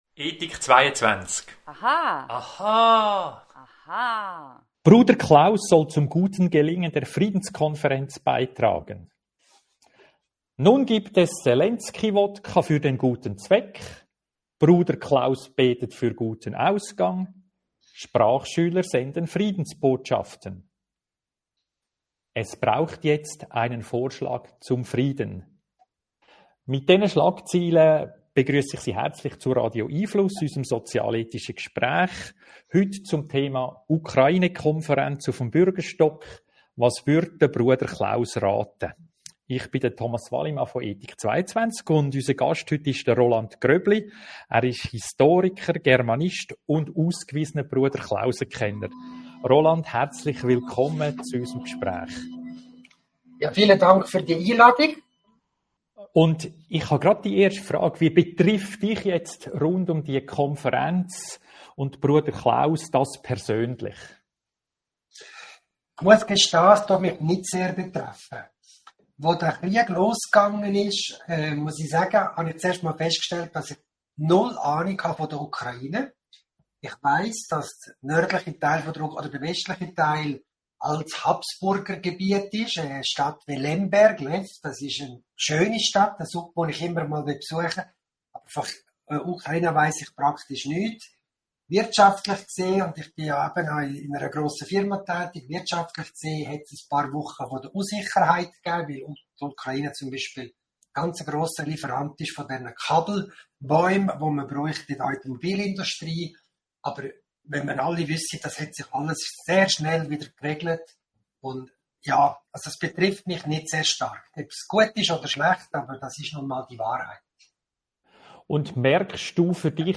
Radio🎙einFluss Podcasts hören Bleiben Sie über die kommenden Radio🎙einFluss Audio-Gespräche informiert!